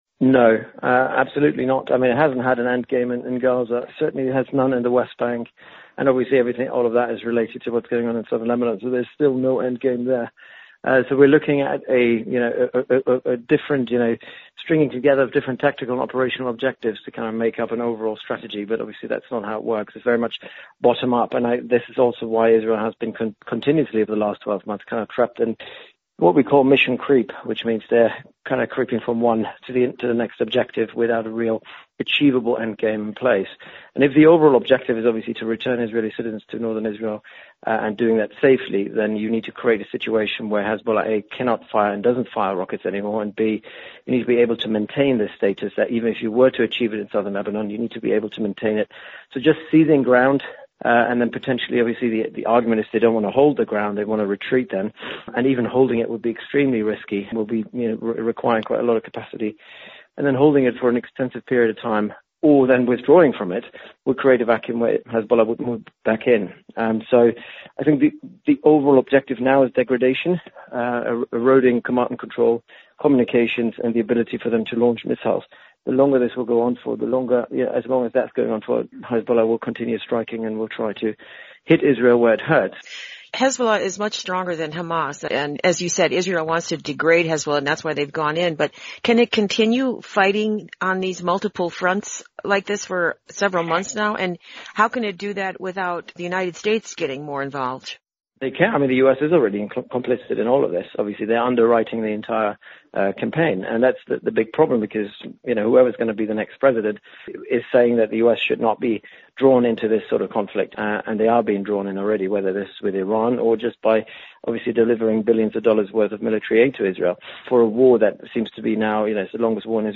Analysis: Israeli airstrike hits Beirut